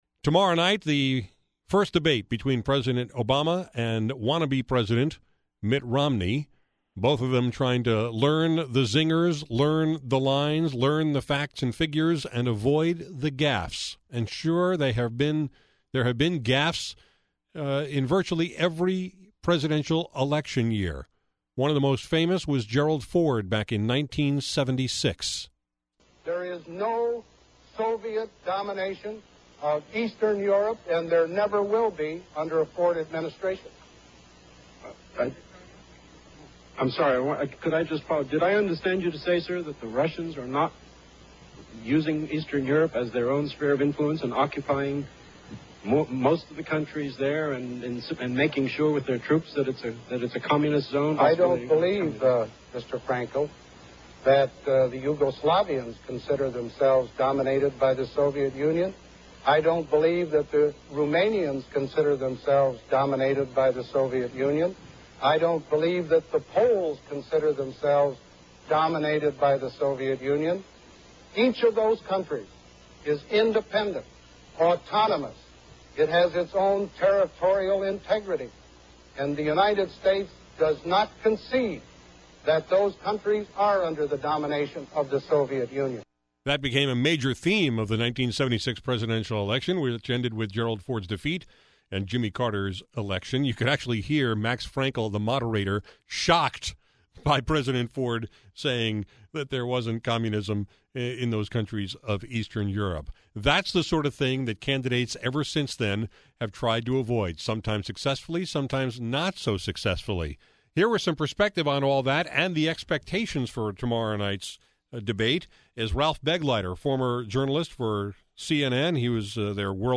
Here’s my conversation with Ralph Begleiter (formerly of CNN, now director of the Center For Political Communication at the University of Delaware) about tomorrow night’s presidential debate.